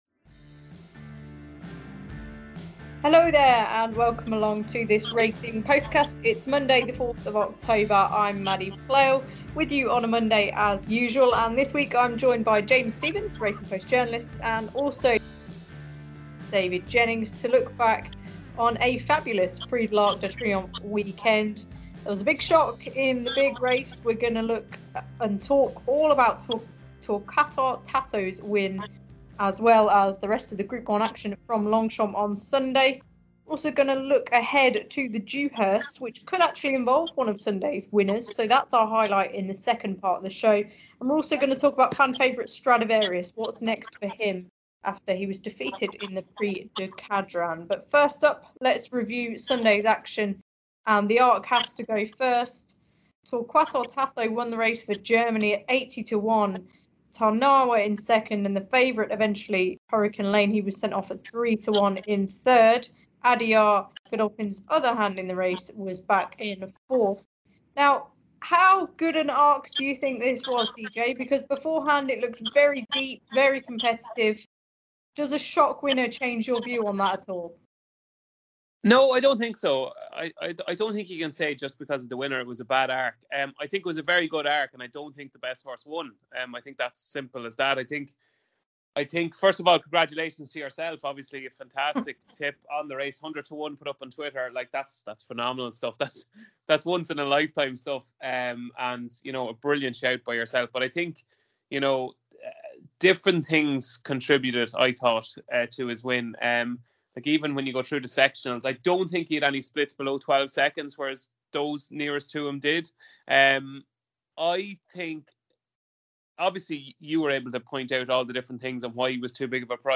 The panel discuss the great stayer.